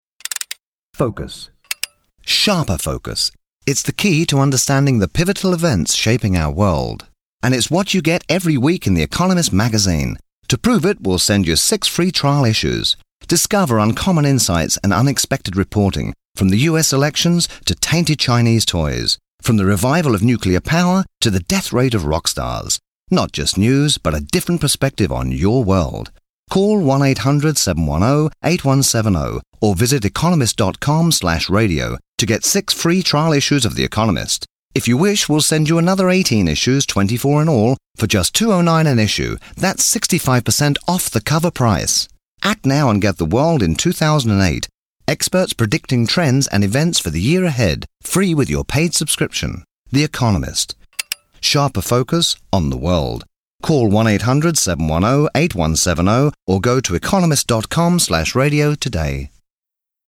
BRITISH ENGLISH DISTINCT ALL ENGLISH ACCENTS, SEXY, SMOOTH,DEEP, INVITING British voice, Classy, Conversational, informative, interesting, Commanding, Believable, Smooth, hard sell.
Sprechprobe: Industrie (Muttersprache):